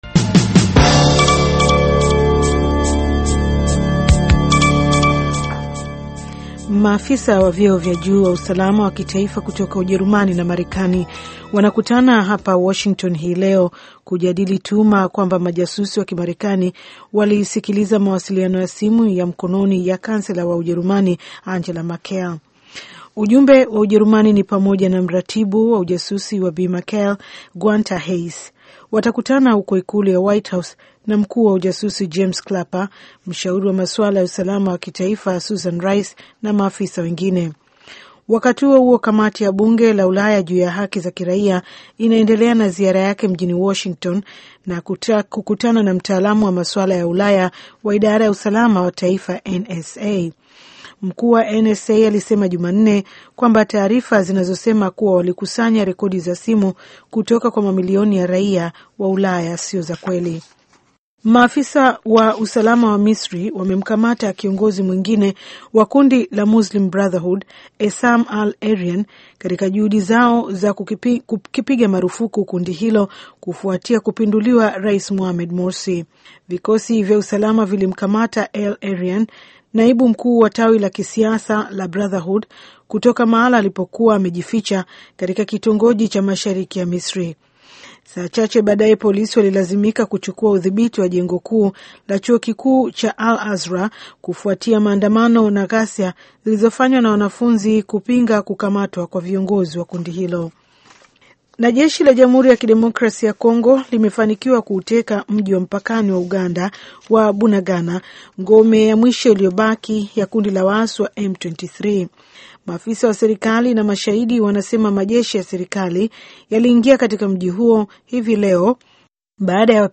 Taarifa ya Habari VOA Swahili - 6:42